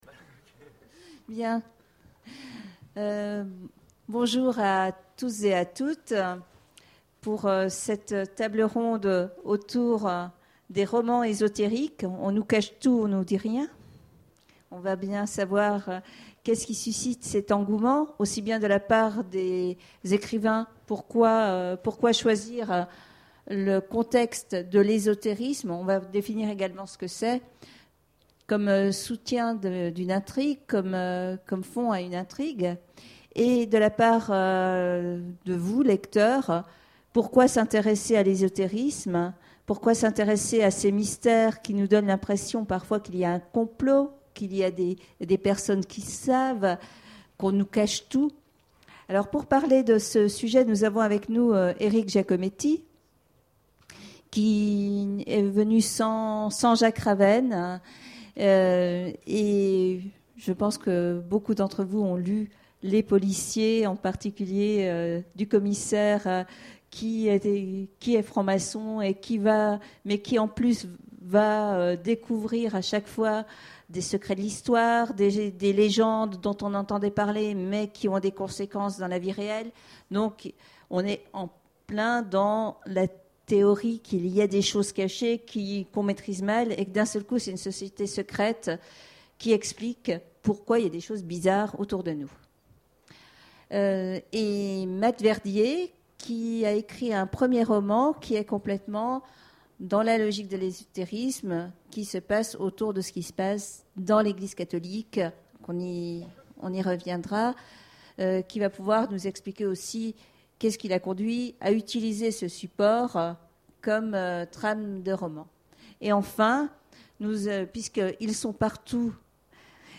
Imaginales 2014 : Conférence Romans ésotériques... Le goût du mystère